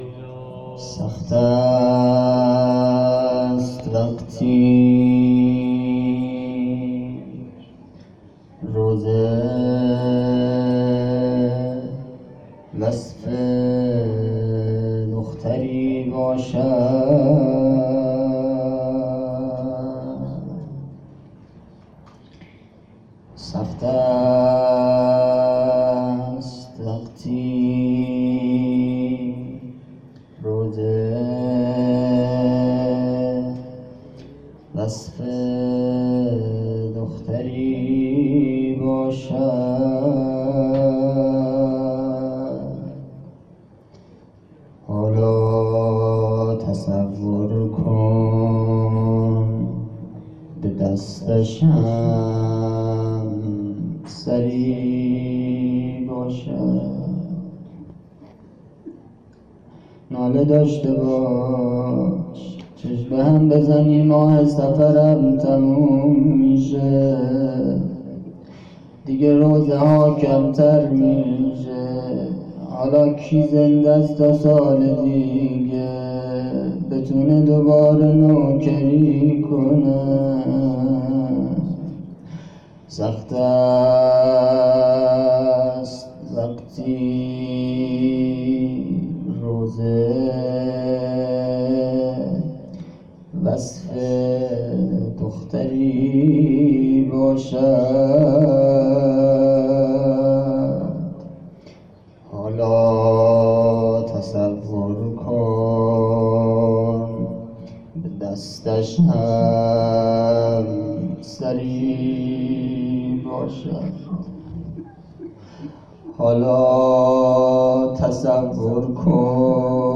[روضه] - [حضرت رقیه(س)] - سخت است وقتی روضه وصف دختری باشد - شب 5 صفر 1401